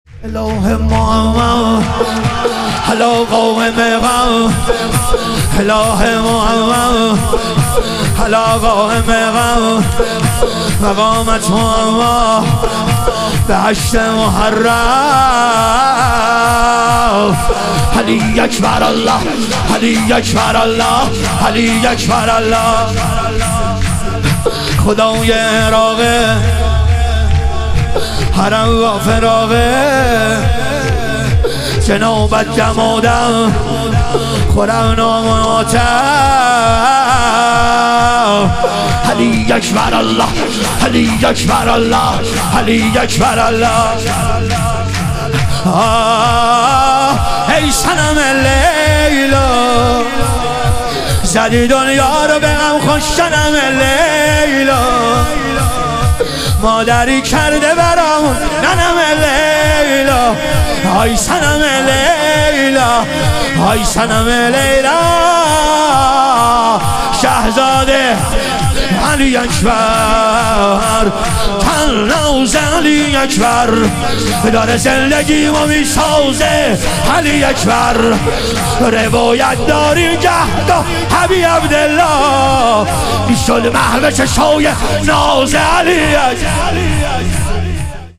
ظهور وجود مقدس امام جواد و حضرت علی اصغر علیهم السلام - شور